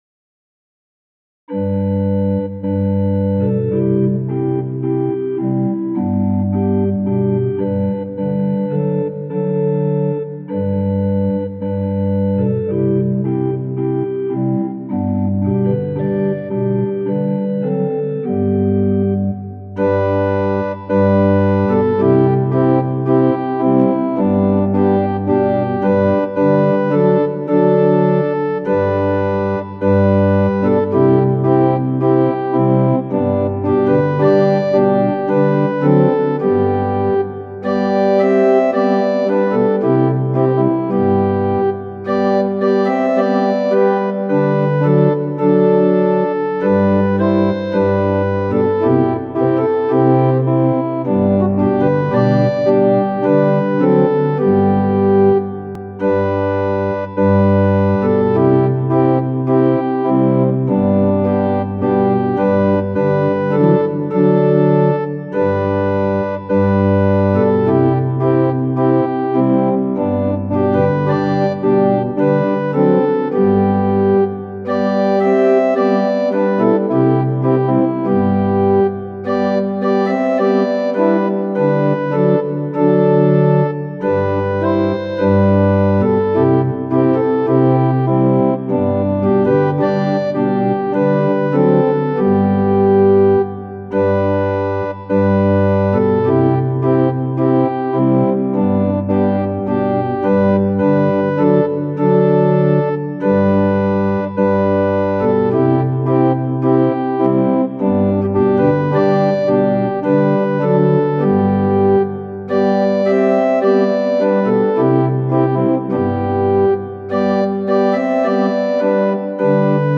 ♪賛美用オルガン伴奏音源：
・短い前奏があります
・間奏は含まれていません
Tonality = G
Pitch = 440
Temperament = Equal